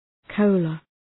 Προφορά
{‘kəʋlə}